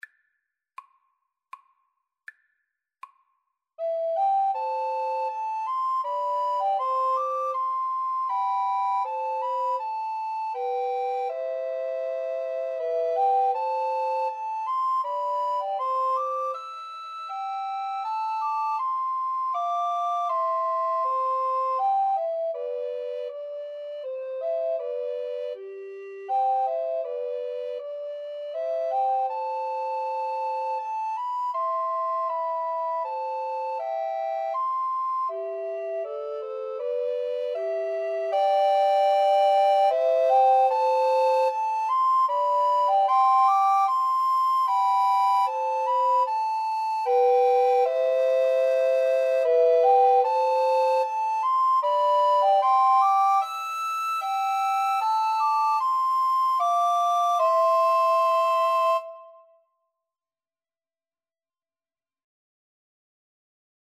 Free Sheet music for Recorder Trio
Alto RecorderTenor Recorder 1Tenor Recorder 2
3/4 (View more 3/4 Music)
Andante
Classical (View more Classical Recorder Trio Music)